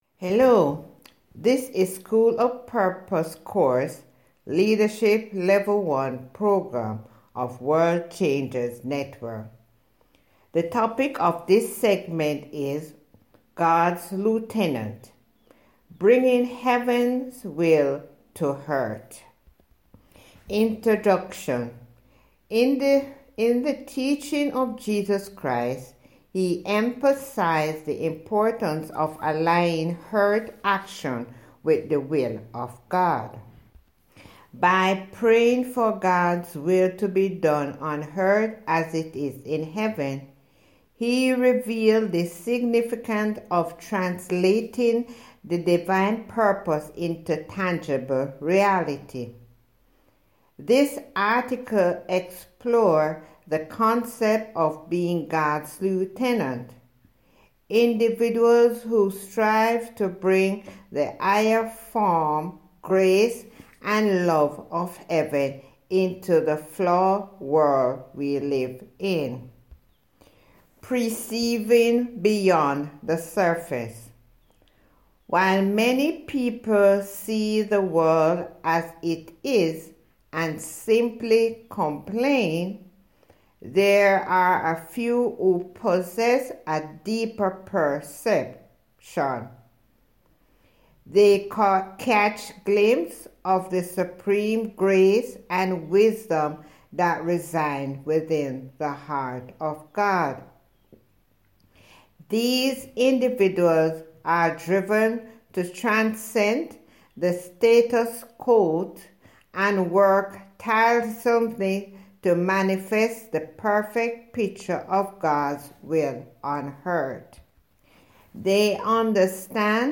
Listen to the human voice reading here: